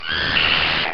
Звук атаки хедкраба в Half-Life